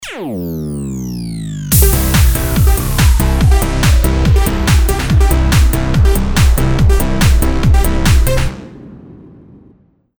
Ballermann sound produzieren z.B. Ikke hüftgold, Lorrenz büffel
Also ich hab null komma null Ahnung wie man das produziert, aber wenn ich mich in Cubase durch die EDM wat weiß ich Toolbox wühle, hab ich 2min was gebastelt Anhänge PizzaBaller.mp3 PizzaBaller.mp3 398,6 KB · Aufrufe: 2.772